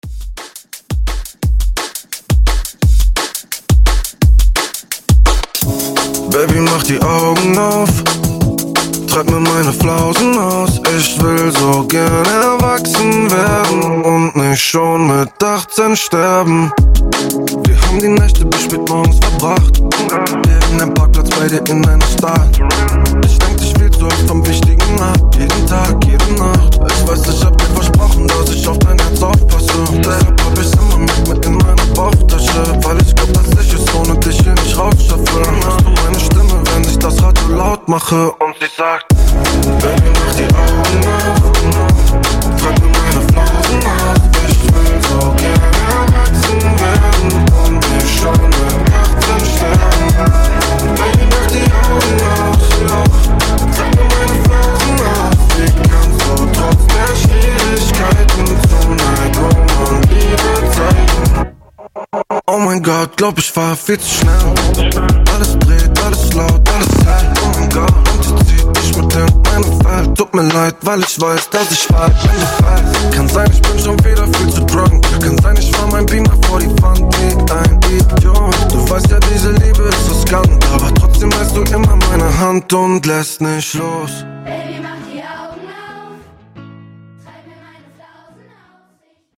Genre: DANCE
Clean BPM: 128 Time